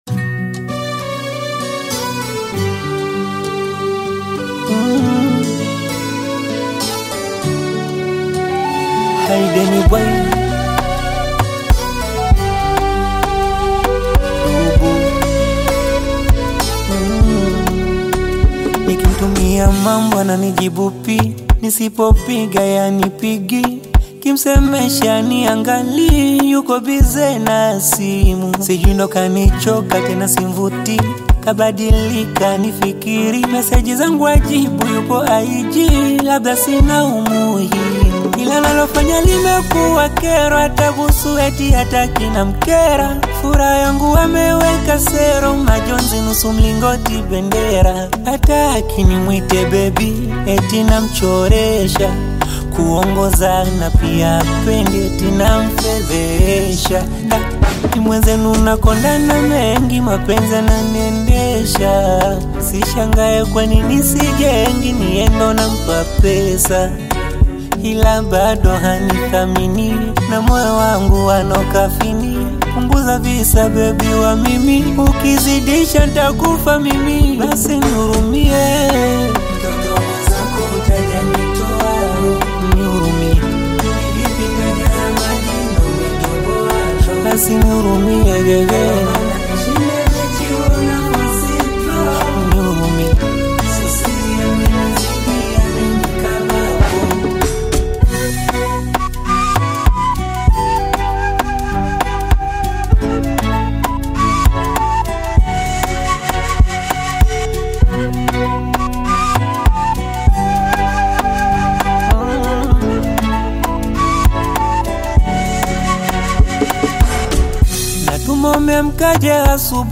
Bongo Flava music track
Tanzanian Bongo Flava artist, singer, and songwriter
Bongo Flava song